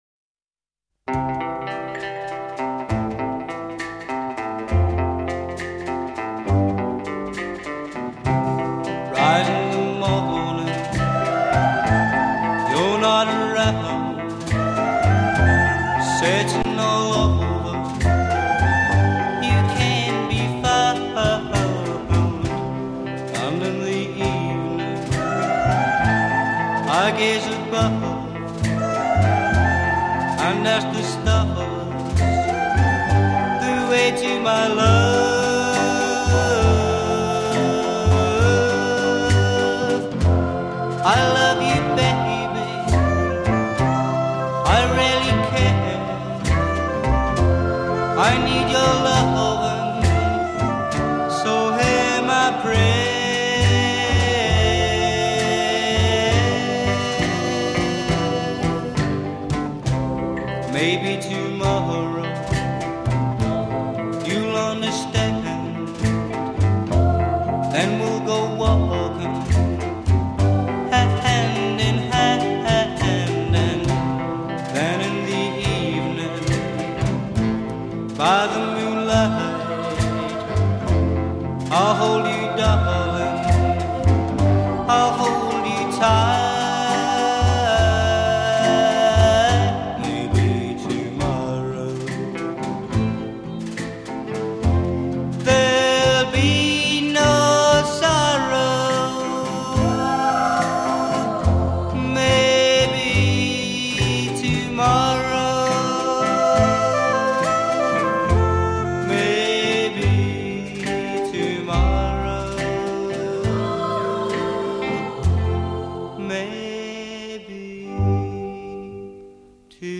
vocals and guitar
chorus 11   add soprano saxophone responses c
coda 17   return of soprano saxophone f